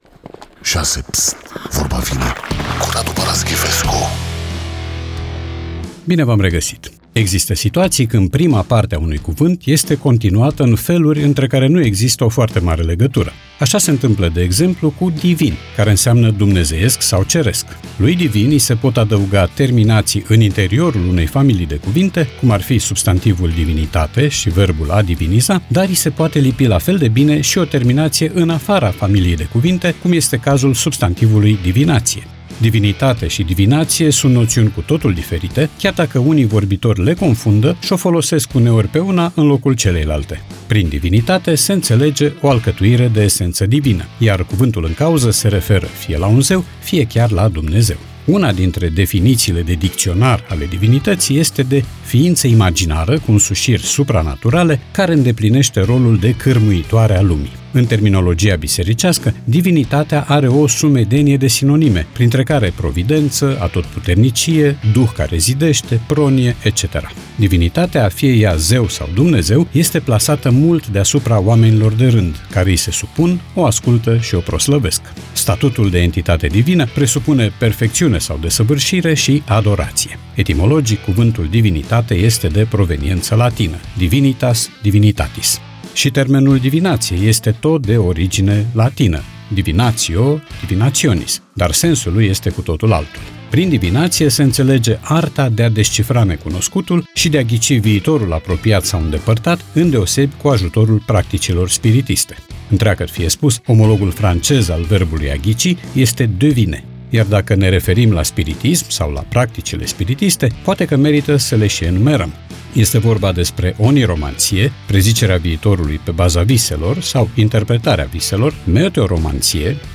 Podcast 27 mai 2025 Vezi podcast Vorba vine, cu Radu Paraschivescu Radu Paraschivescu iti prezinta "Vorba vine", la Rock FM.